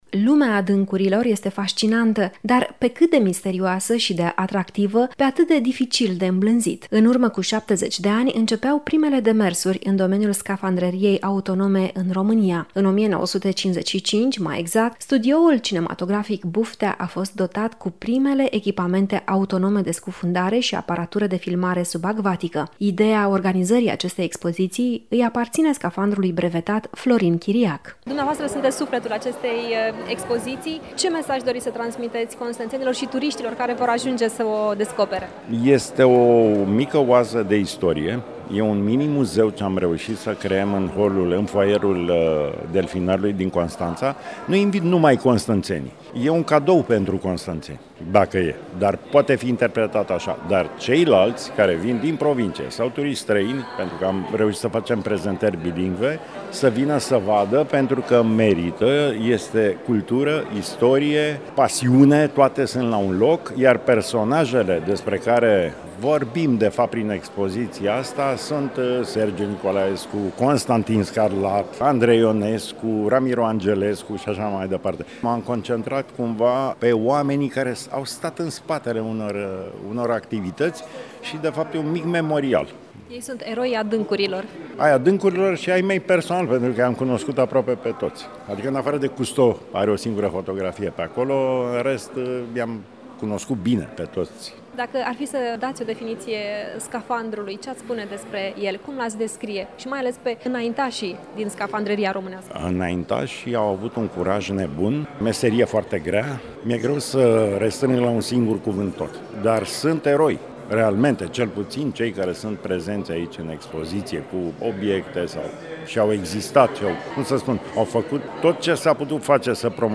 reportaj